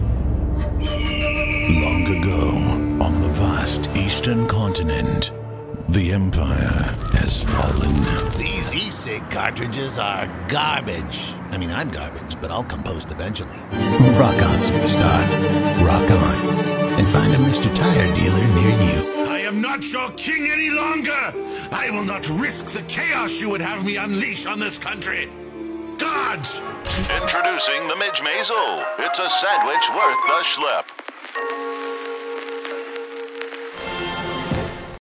Variety
All vocals are recorded in a professional studio with state of the art equipment including Sennheiser MKH-416, Avalon 737 preamp, and Adobe Audition DAW.